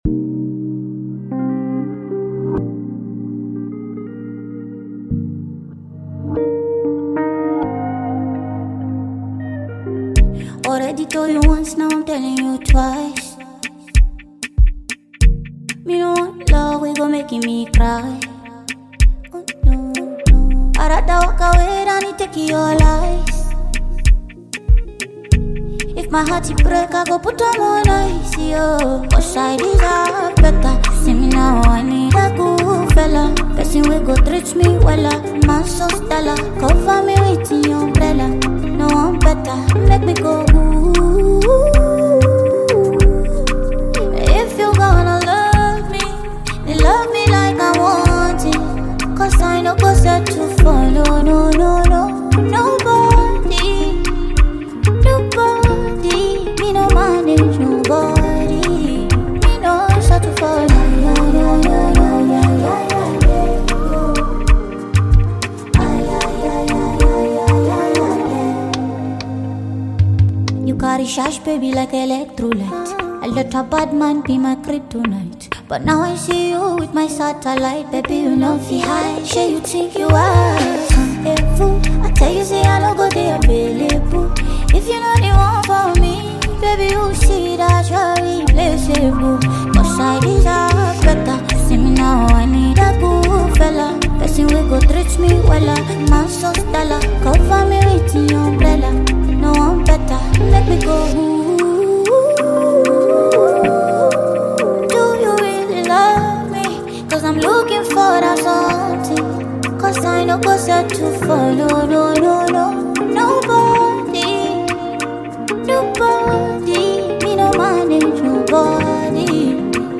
vocal powerhouse